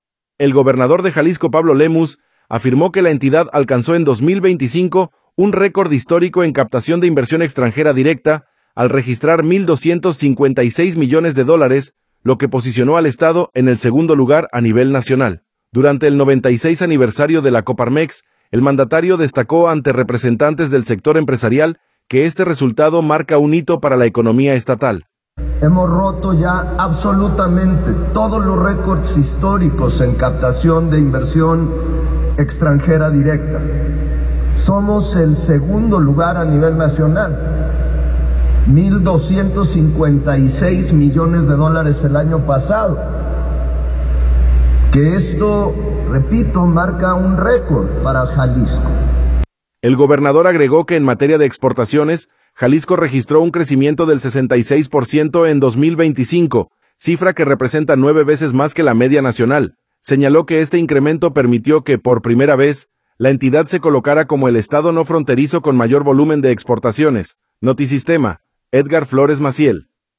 audio El gobernador de Jalisco, Pablo Lemus, afirmó que la entidad alcanzó en 2025 un récord histórico en captación de inversión extranjera directa, al registrar mil 256 millones de dólares, lo que posicionó al estado en el segundo lugar a nivel nacional. Durante el 96 aniversario de la Coparmex, el mandatario destacó ante representantes del sector empresarial que este resultado marca un hito para la economía estatal.